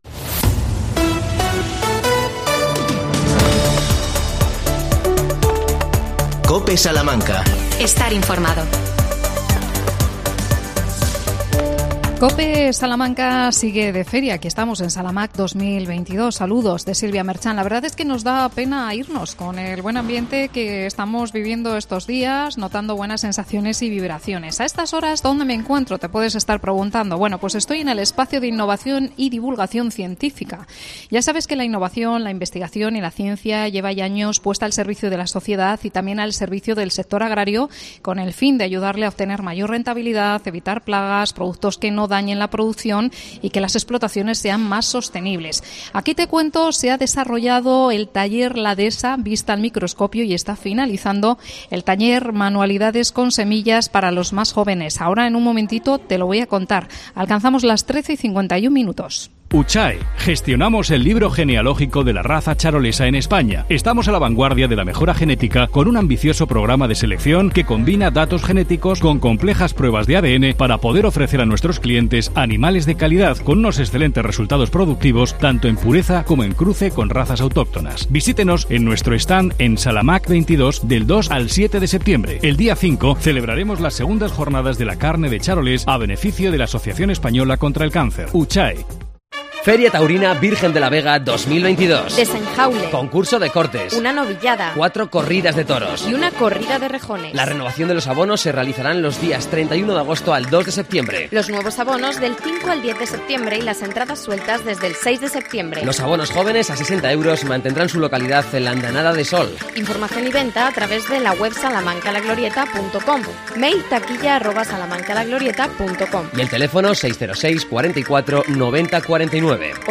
AUDIO: Programa especial desde la feria Salamaq 2022. ( 3ª parte)